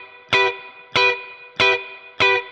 DD_StratChop_95-Emin.wav